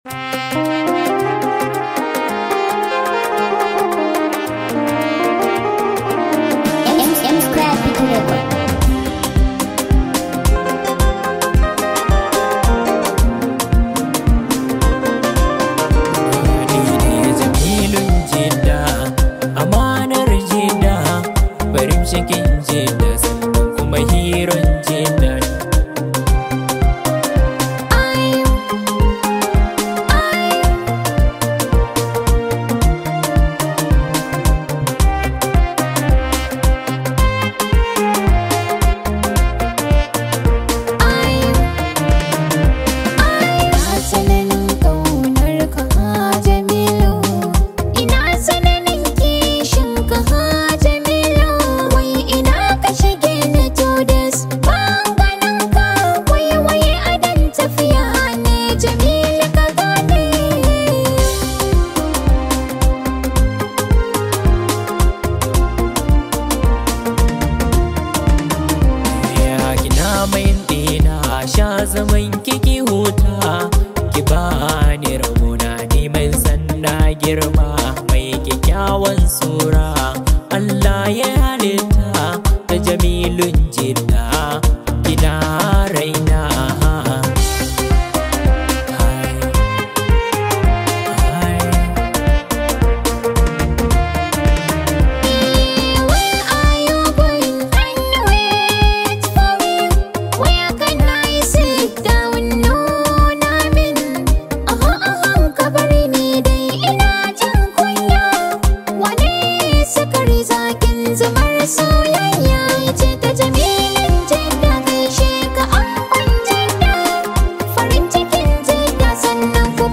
much appreciated hausa song known as
high vibe hausa song